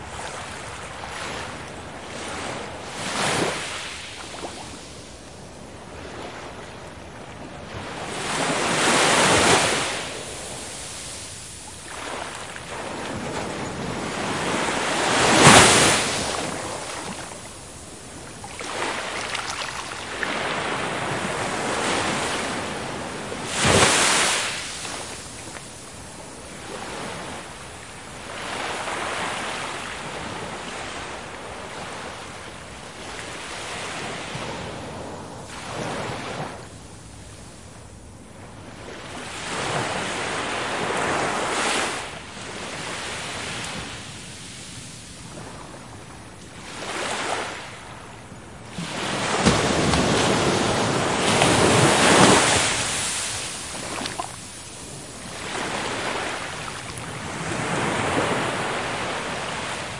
描述：浪花飞溅在沙滩上。这个样本是不寻常的，因为海浪很强，但根本没有风。因此，相对安静的时刻在此期间，你可以听到沙子移动、泡沫爆裂和非常遥远的海浪声强调了海浪的挤压。这种模式给人（至少对我来说）一种周围有很大空间的感觉。在韦尔瓦的Punta del Moral（西班牙南部安达卢西亚）附近录制，使用一对Primo EM172胶囊（没有挡风玻璃，一个在我胸前，另一个在我背上），Fel前置放大器，以及PCM M10录音机.
标签： 海岸 海洋 海滨 沙滩 海浪 飞溅 现场记录 冲浪
声道立体声